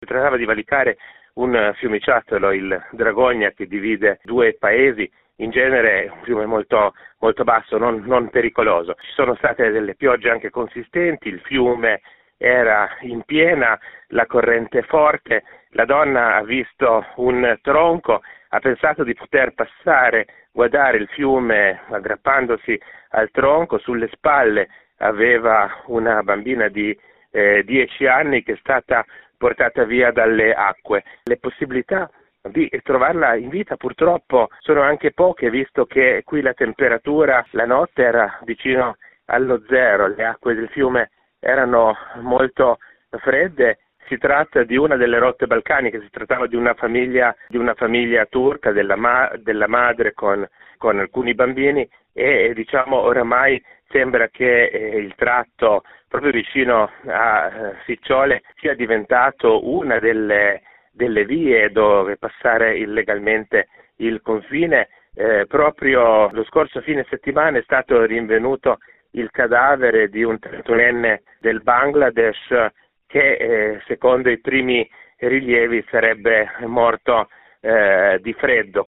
il racconto